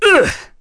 Roman-Vox_Damage_03.wav